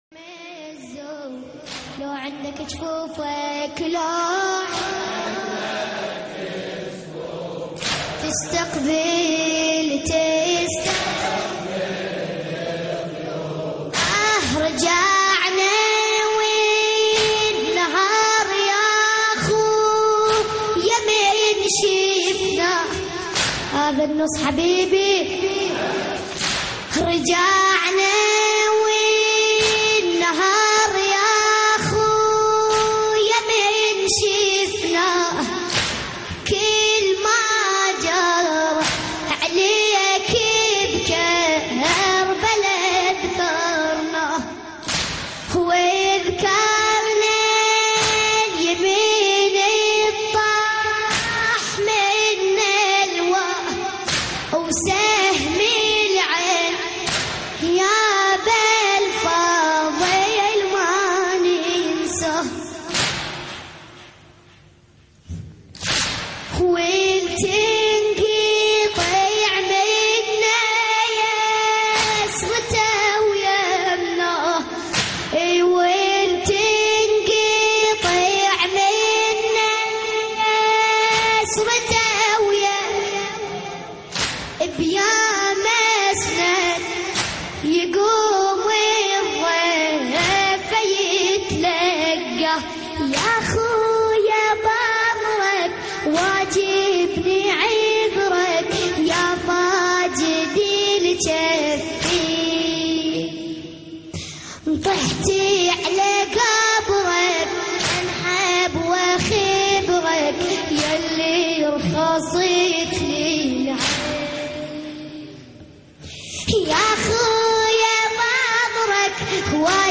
لطميات محرم